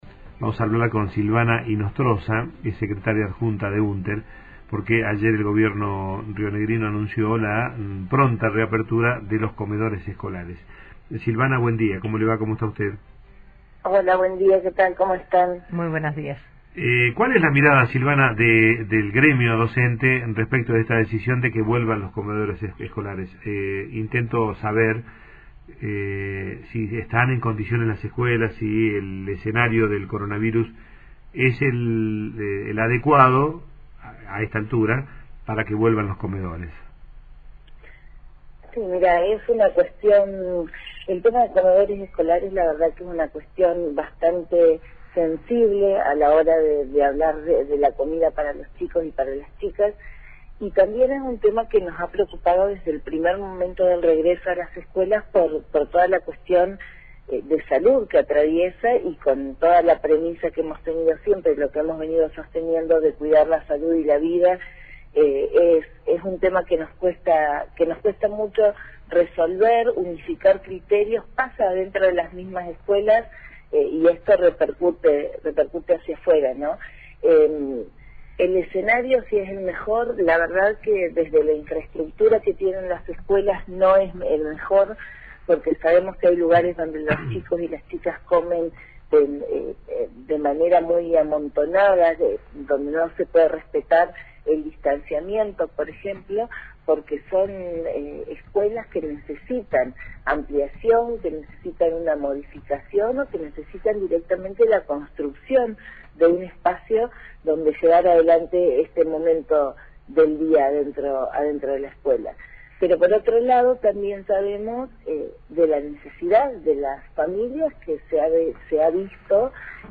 Entrevista de radio